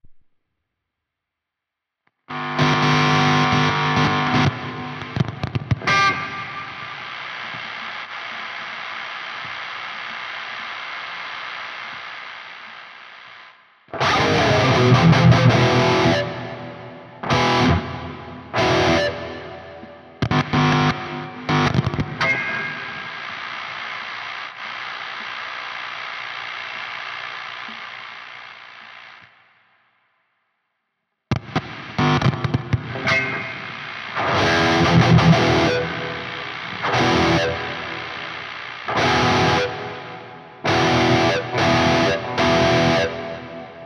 EMG PUs rauschen nach Einstecken für ein paar Sekunden
Wenn ich einen verzerrten Verstärker nutze und das Gitarrenkabel einstecke rauschen die PUs für ein paar Sekunden bevor das Rauschen wieder verschwindet.
Die ersten zwei Sekunden sind nur Nabengeräusche vom einstecken. Danach stecke ich das Kabel mehrfach wieder ein.